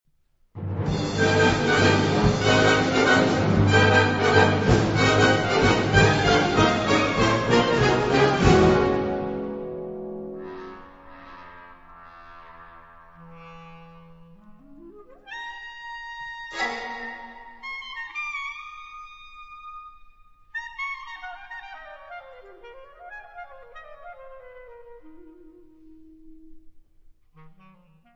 Unterkategorie Zeitgenössische Bläsermusik (1945-heute)
Besetzung Ha (Blasorchester)